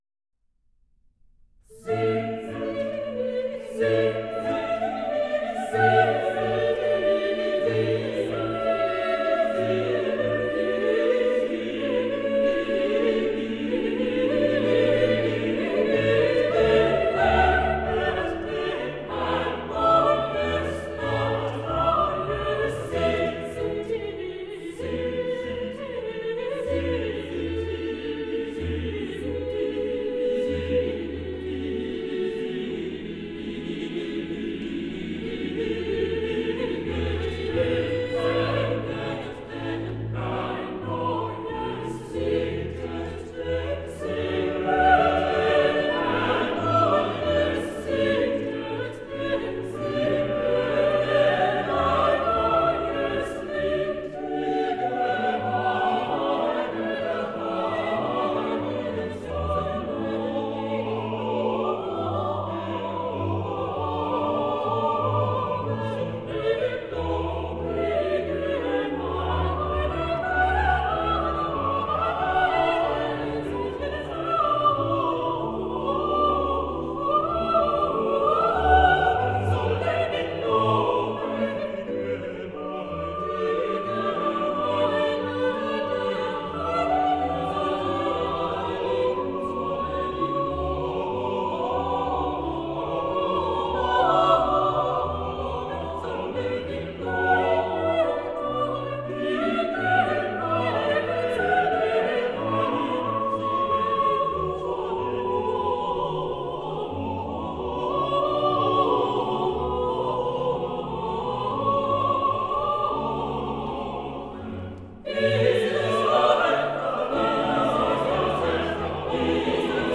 Motet for Eight Voices in double Choir.
Soprano
Alto
Tenor
Baritone